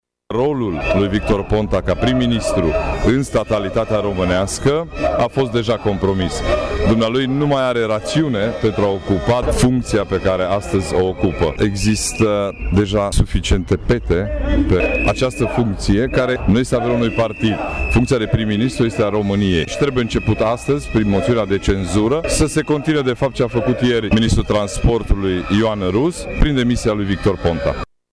Aproximativ 150 de oameni s-au adunat astăzi în fața Prefecturii Mureș, pentru a protesta împotriva Guvernului și a susține moțiunea de cenzură a PNL.